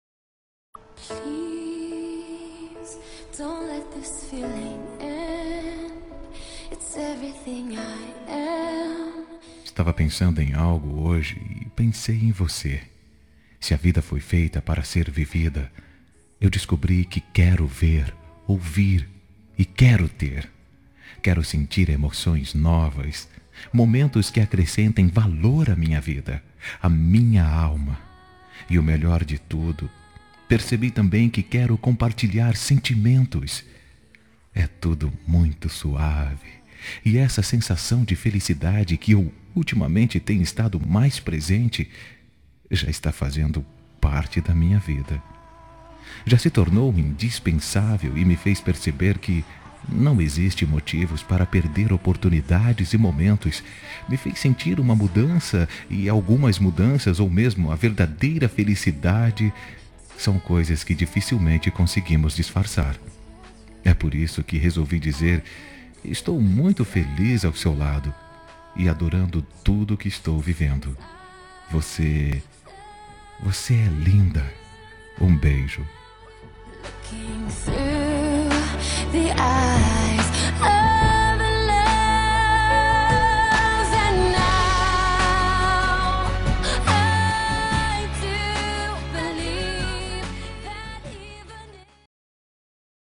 Telemensagem Início de Namoro – Voz Masculina – Cód: 756